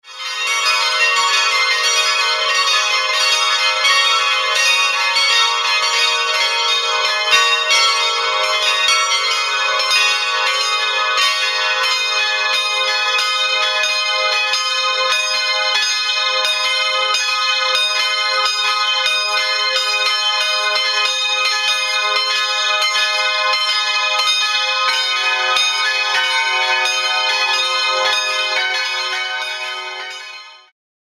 The bells of St Anna’s chapel
Langsam verklingen die Glocken.
Bells of St Anna